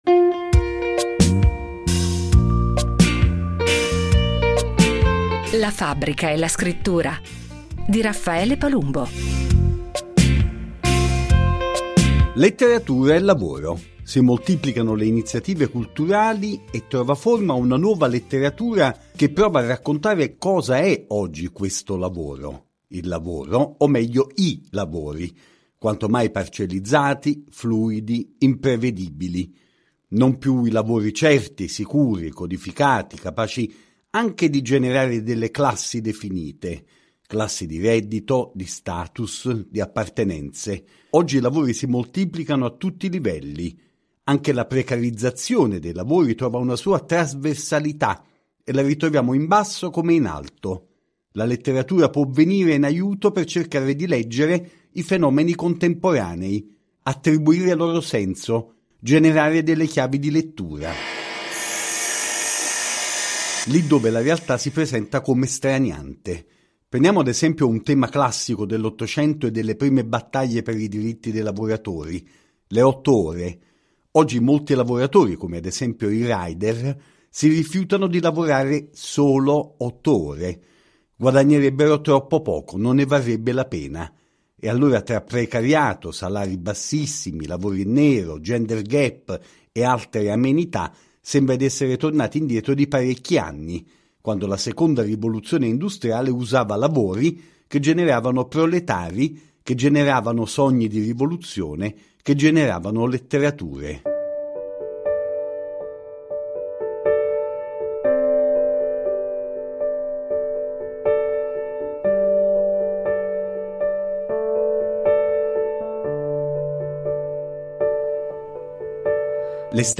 ha raccolto le voci e le testimonianze di scrittori e attivisti che si sono dati appuntamento alla terza edizione del Festival di letteratura working class di Campi Bisenzio, in Toscana.